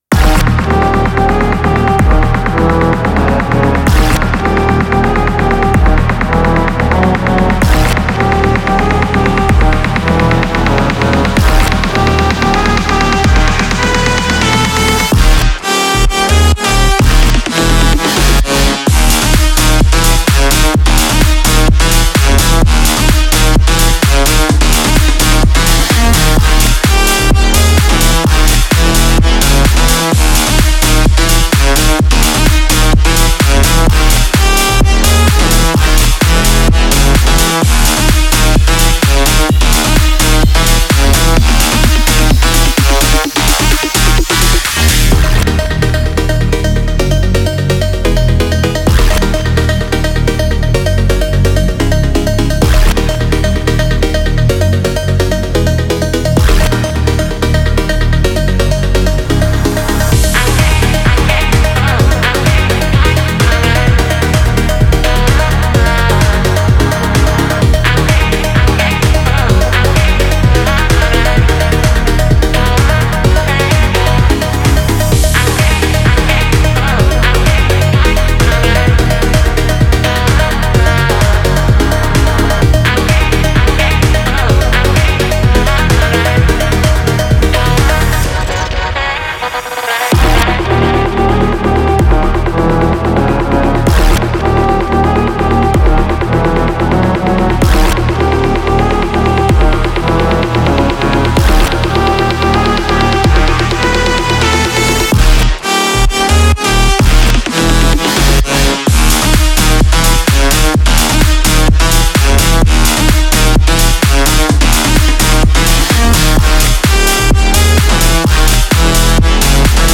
EDM(ダンスミュージック)というジャンルの曲なのですが、YouTubeやこのブログでも紹介しています。